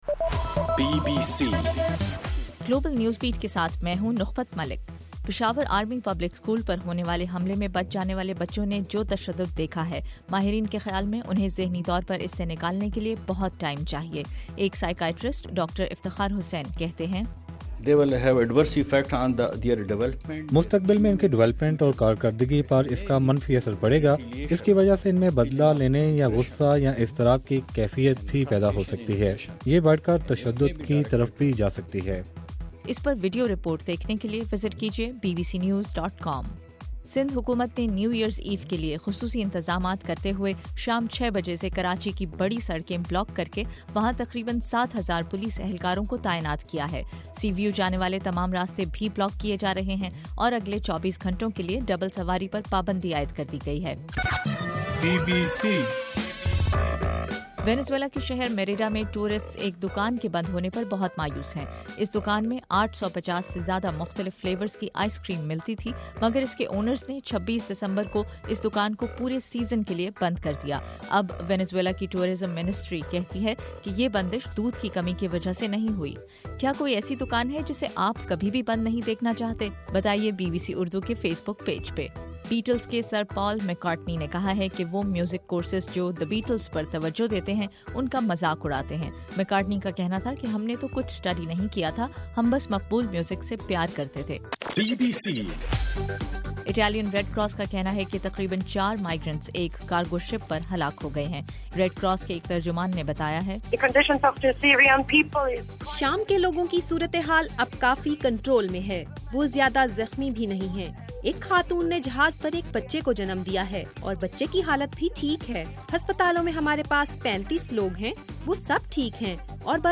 دسمبر 31: رات 10 بجے کا گلوبل نیوز بیٹ بُلیٹن